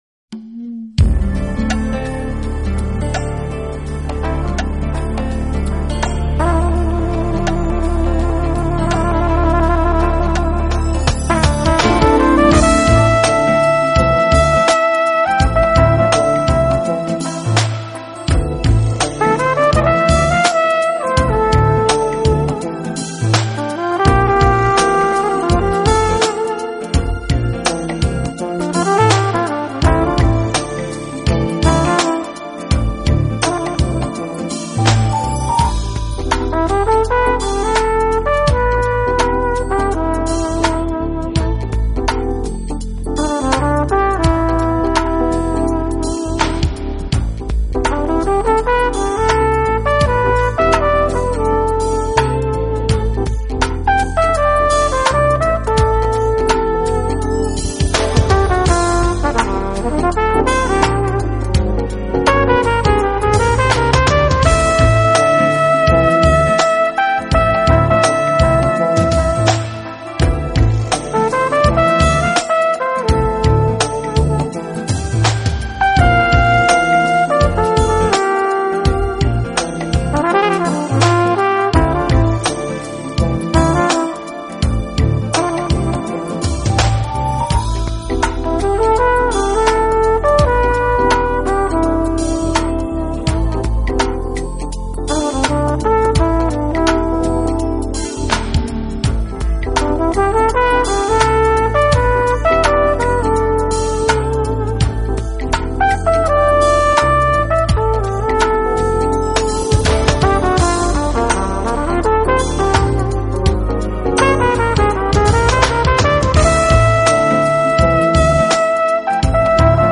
到自家录音室以便利不计时数将音乐性修整完美，演奏的乐器也从小号增加键盘、翼号，
借重了大量的键盘音效，幽幽迷濛的翼号描绘着夜幕低垂的天色